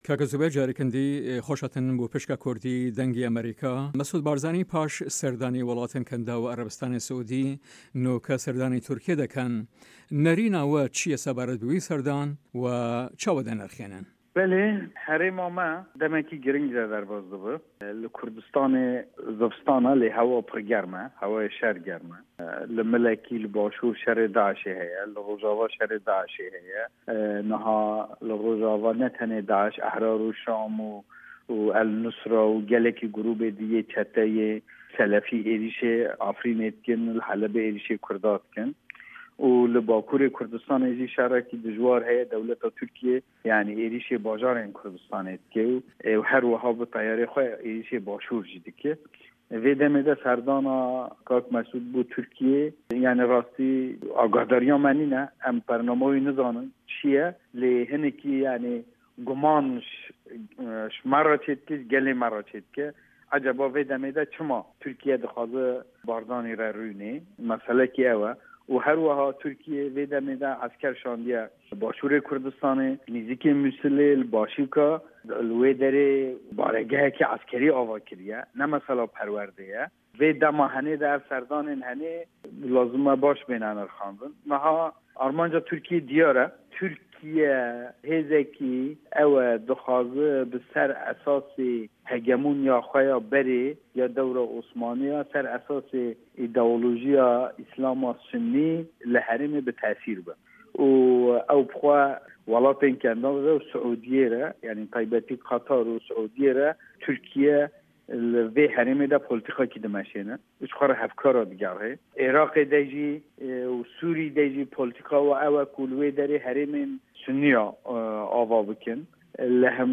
زوبێر ئایدار ئەندامی کۆنسەیا کۆما جڤاکن کوردستان KCK لە هەڤپەیڤینێکدا لەگەڵ بەشی کوردی دەنگی ئەمەریکا دەڵێت" بەڵی هەرێمی ئێمە بە قوناغێکی گرنگ تێدەپەرێت، لە کاتێکدا لە کوردستان زستانە، بەڵام هەوا( با) زۆر گەرمە، مەبەست هەوای شەر گەرمە، لە لایەکەوە لە باشور لە دژی داعش شەر هەیە، لە رۆژئاوا شەر هەیە، نەک هەر داعش بەڵکۆ چەندین گروپی چەتەی تر، وە لە باکوری کوردستانیش شەرێکی دژوار هەیە و لەشکەری تورکیە هێرش دەکاتە سەر باژێرەکانی کوردستان و بە فرۆکەش هێرش دەکاتە سەر باشور، جا لەم کاتەدا سەردانی کاک مەسعود بۆ تورکیا هەرچەند ئێمە ئاگادار نیین و نازانین بەرنامەی چیە، بەڵام لامان و لای گەلی کوردمان گومان درووست دەکات ئاخۆ بۆ تورکیا لەم کاتەدا بارزانی بانگهێشت دەکات.